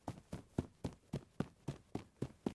02_孤儿院走廊_小孩踢球.ogg